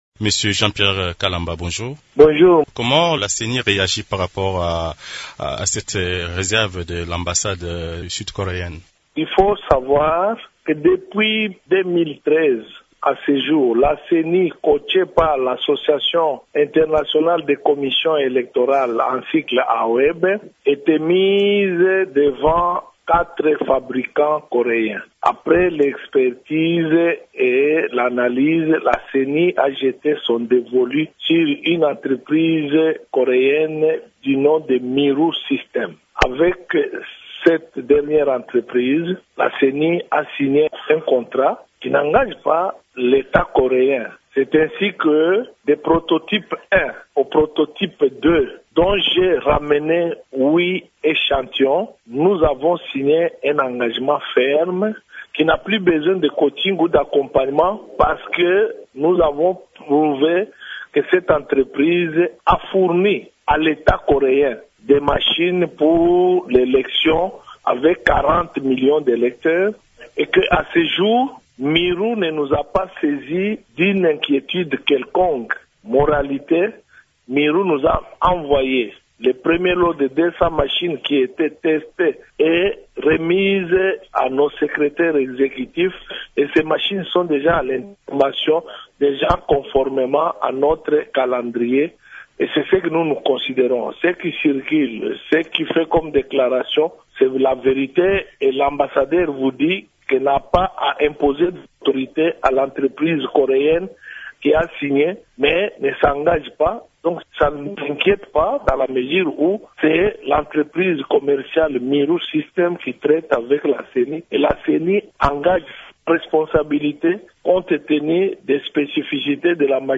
Dans une interview accordée mardi 3 avril à Radio Okapi, il souligne que le fabricant sud-coréen des machines à voter n’a pas saisi à ce jour son institution sur des problèmes que poserait l’utilisation de ces machines.